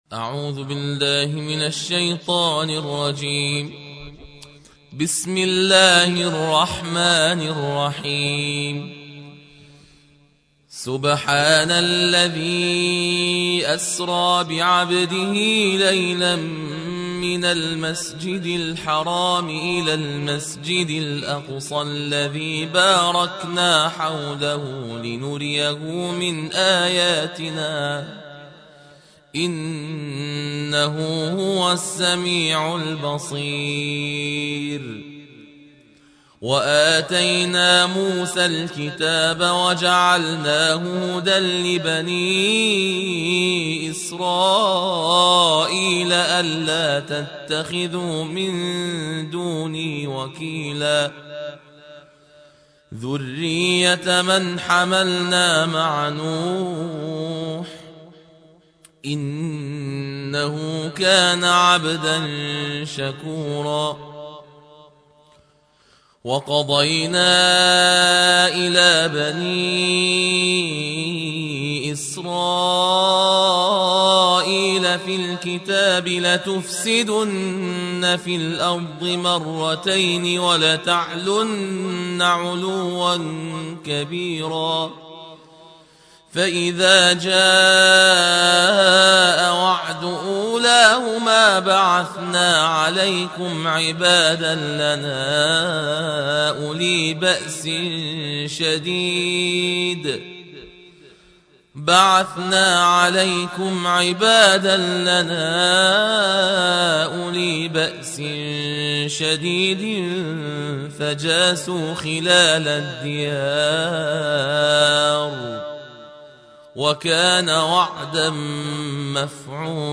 الجزء الخامس عشر / القارئ